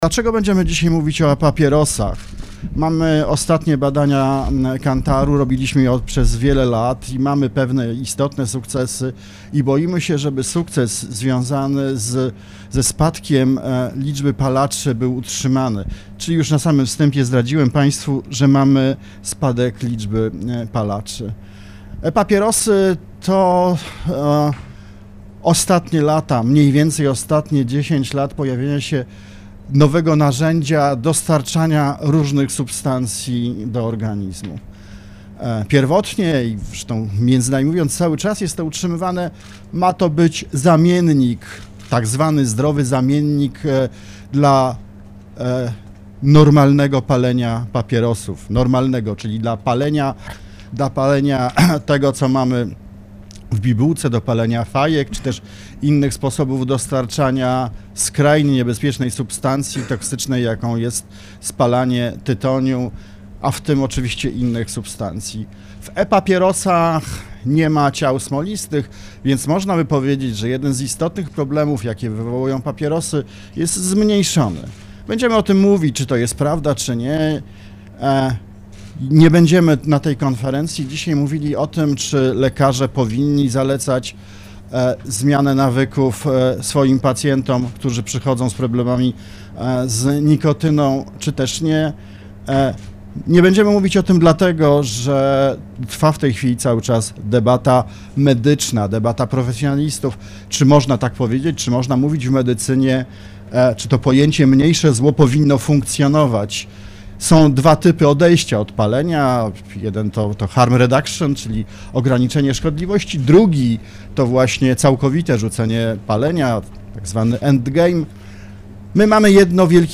Główny Inspektor Sanitarny doktor Jarosław Pinkas powiedział Radiu Warszawa, że zdaniem młodzieży atrakcyjniejsze od tradycyjnych papierosów są te elektroniczne.
Całą wypowiedź Głównego Inspektora Sanitarnego doktora Jarosław Pinkasa można wysłuchać poniżej: